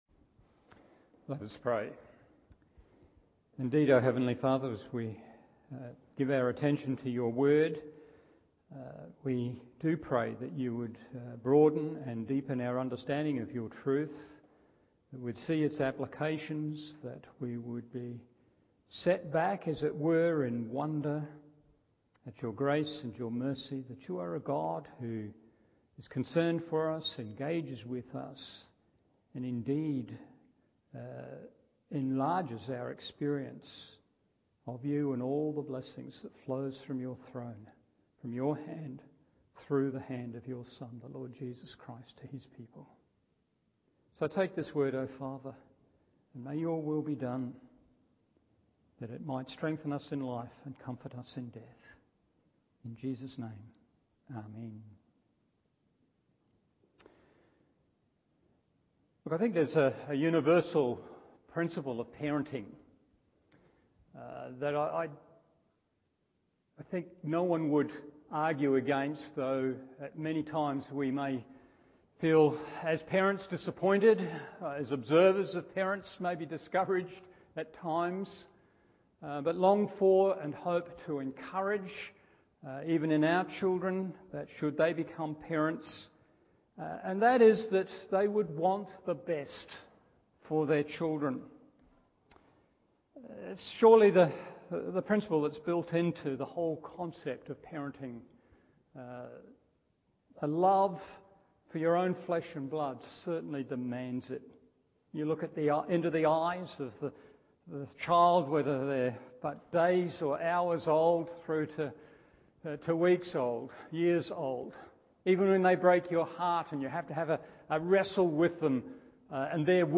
Evening Service 1 Thessalonians 3:11-13 1.